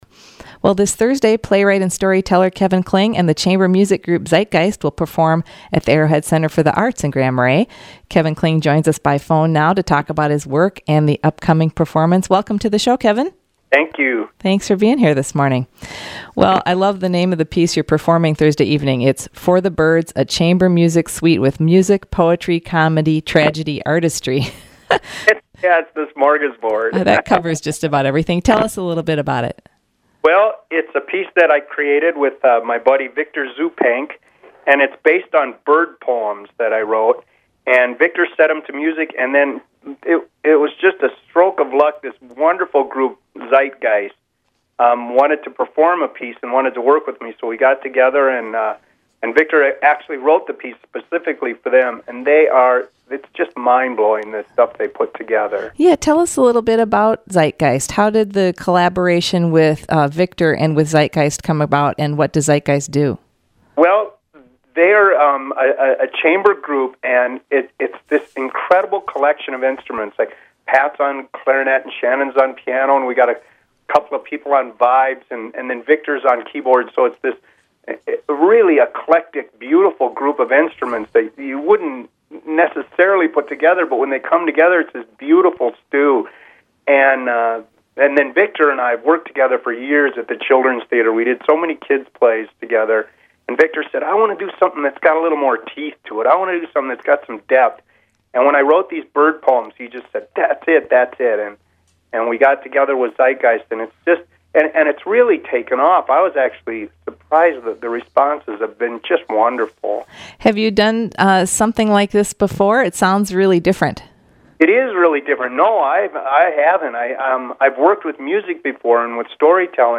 (Attached mp3 features an interview with Kevin Kling recorded live on WTIP's A.M. Calendar program, Monday, January 30th.)